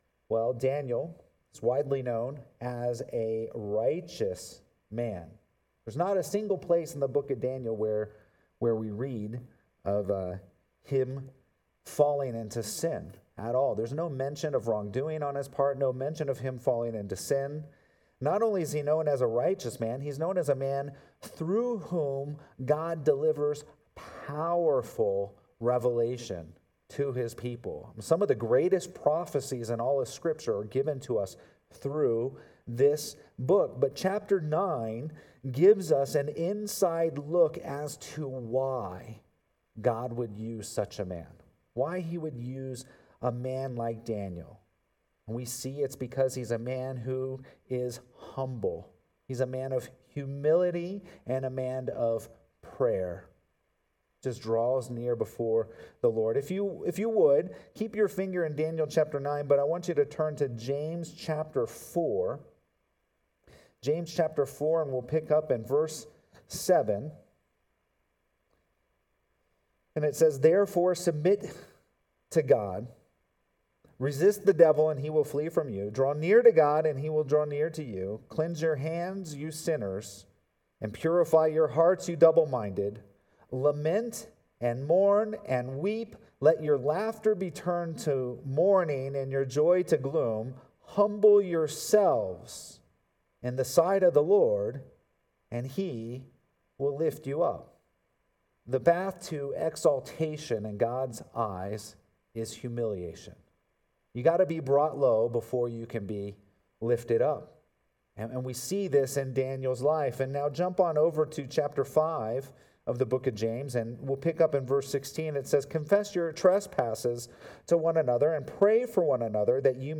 Sermons | Redemption Hill Church